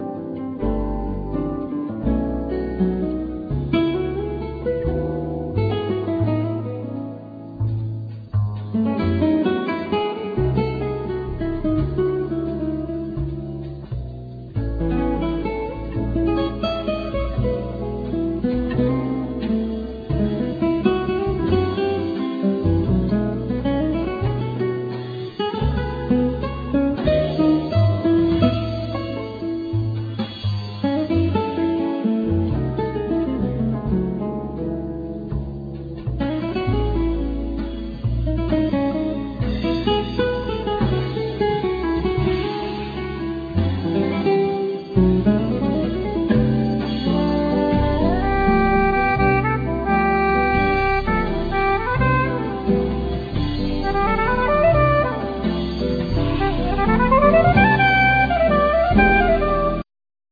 Classical&12 String Guitar,Synthsizer,Piano
Bass
Peucussions,Vocal
Drums,Hand Drums